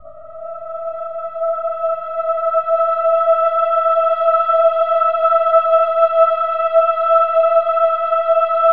OH-AH  E5 -R.wav